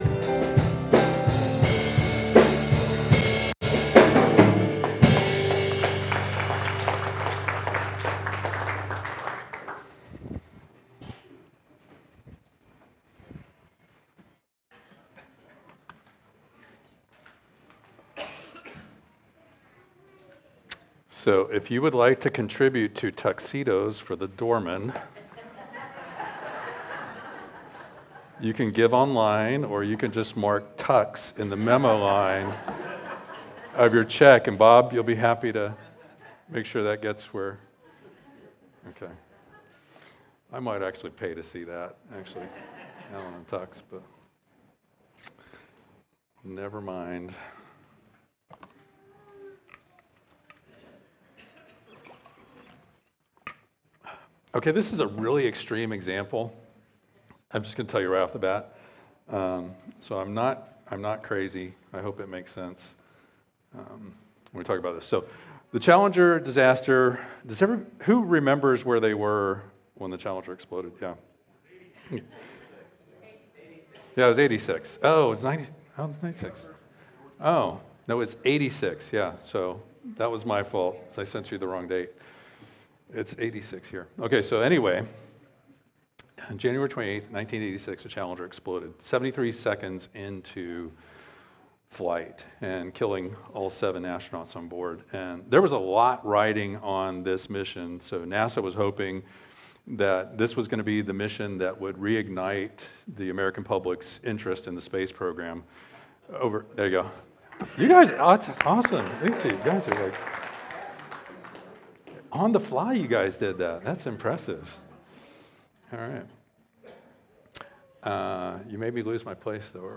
Sermons
Teachings from Lion & Lamb Church: Topeka, KS